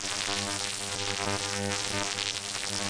SOUND / BACKGROUND / ELECTRIC